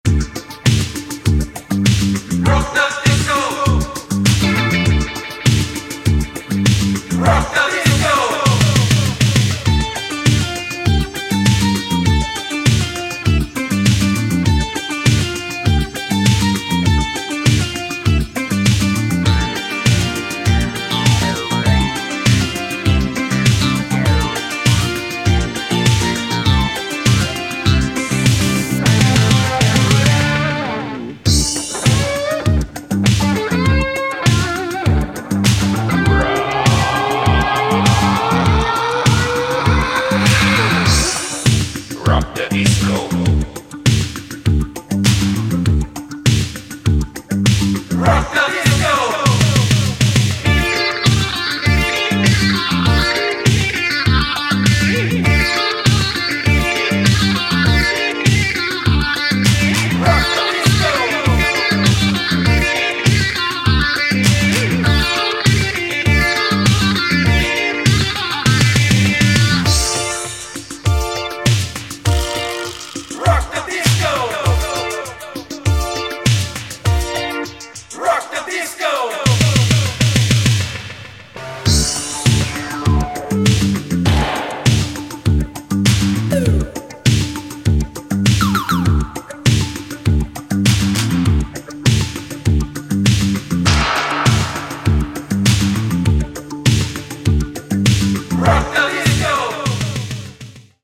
two new electronic funk/rock tracks
guitarist